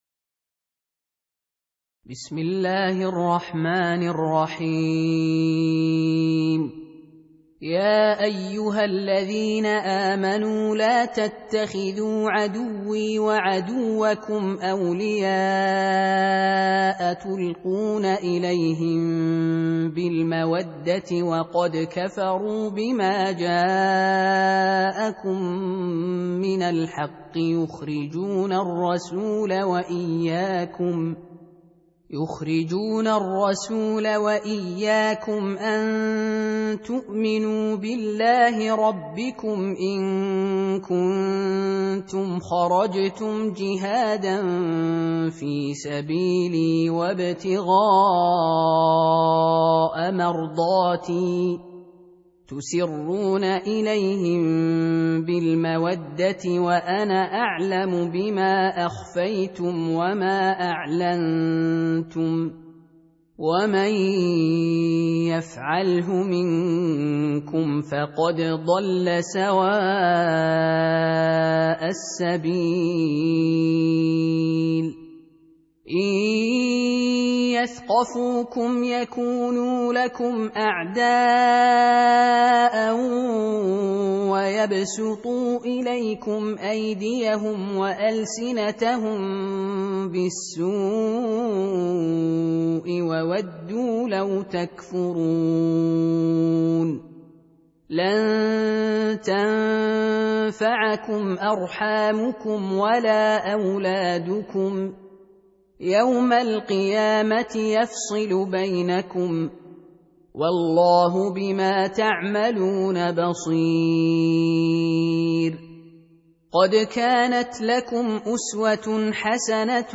Surah Repeating تكرار السورة Download Surah حمّل السورة Reciting Murattalah Audio for 60. Surah Al-Mumtahinah سورة الممتحنة N.B *Surah Includes Al-Basmalah Reciters Sequents تتابع التلاوات Reciters Repeats تكرار التلاوات